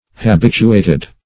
& p. p. Habituated (h[.a]*b[i^]t"[-u]*[=a]`t[e^]d); p. pr.